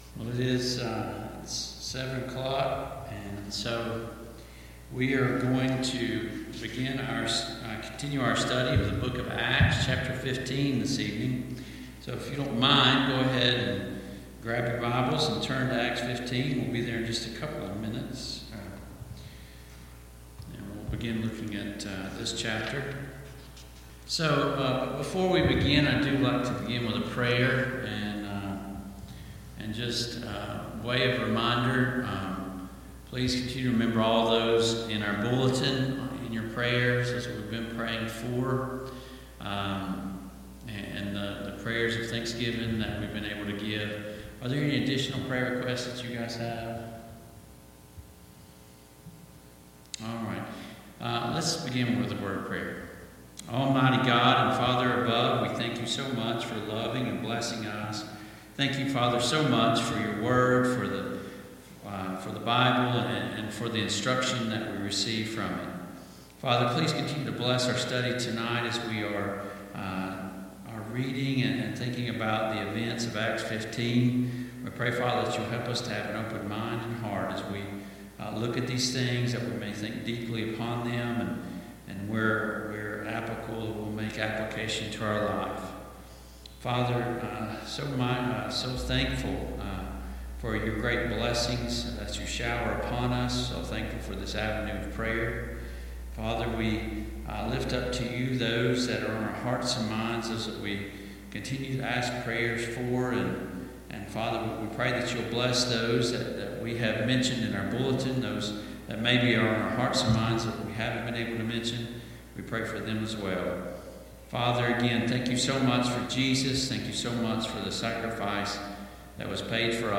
Acts 15:1-12 Service Type: Mid-Week Bible Study Download Files Notes « Does it really matter? 4.